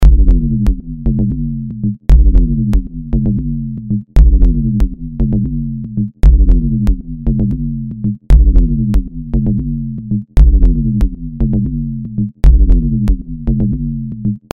Nun aktiviere ich im Pad Editor einen Ringmodulator und steuere dessen Mixanteil und die Tonhöhe über die Wellenform „Bouncing Ball“: Dabei handelt es sich in etwa um die Bewegung, die ein Flummi vollführt, wenn man ihn fallen lässt, umgesetzt in eine Verlaufsform.